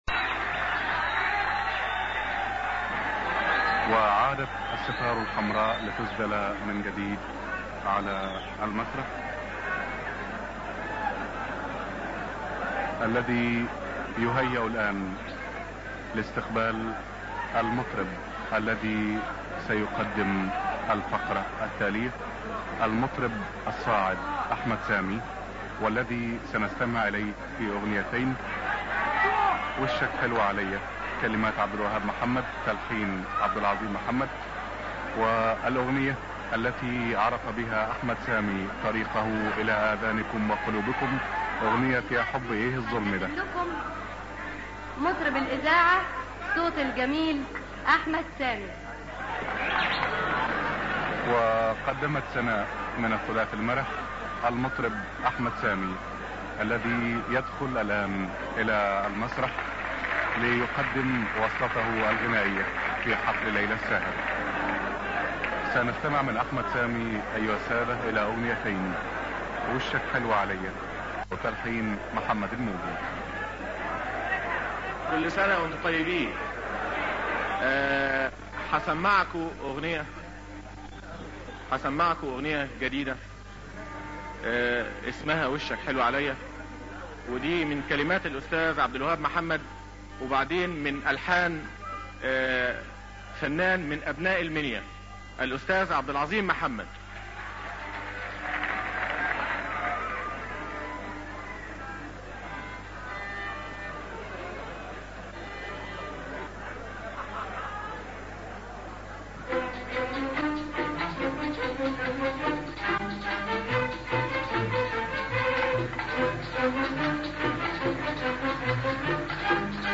اغاني مصرية